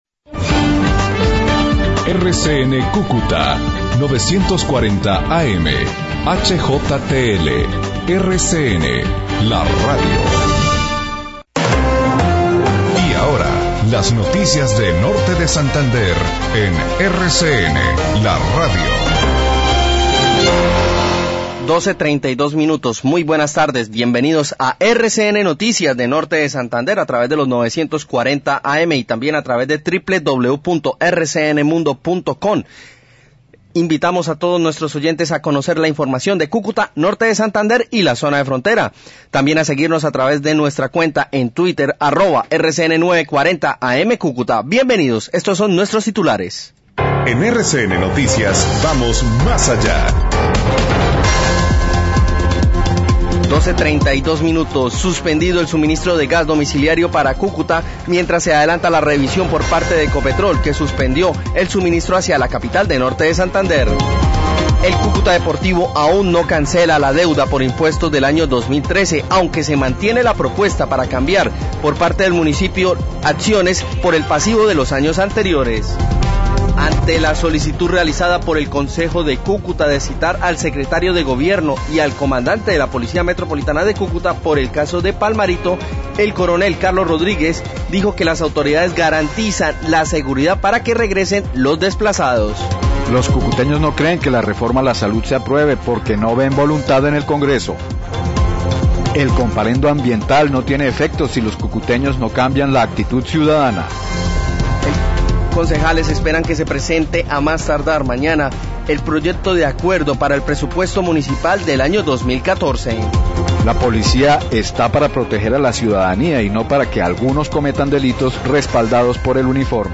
RCN 940AM Cúcuta, noticias de la mañana → Periodistas de RCN Noticias desde RCN Radio Cúcuta 940AM.